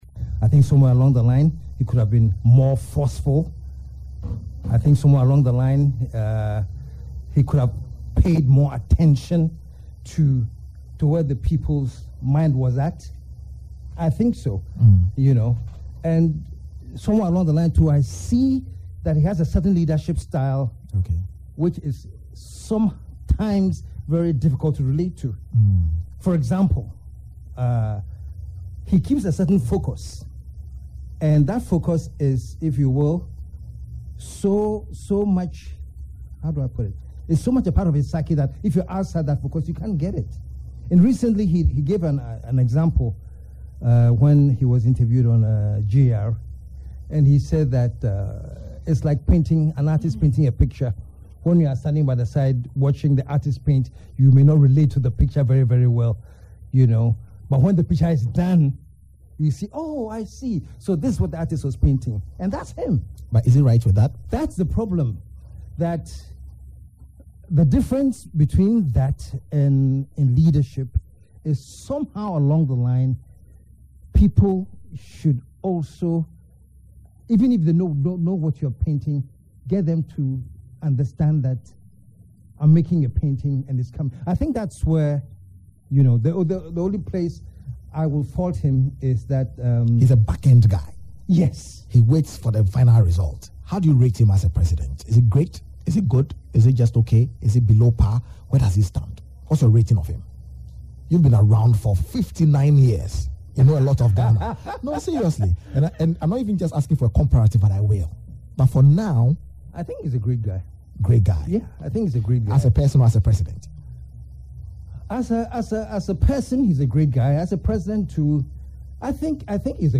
In an interview on Starr FM Thursday morning, KSM, as he is popularly called said President Mahama as a person was “a great guy” but as a leader, “I think he could have been more forceful…paid more attention to the people…”
KSM, who is a cousin to the flagbearer of the New Patriotic Party (NPP), Nana Akufo-Addo, when asked to make a choice between President Mahama and the former, burst out in laughter, saying, “who is a greater guy?…errr…producers of this programme I’m going back home.”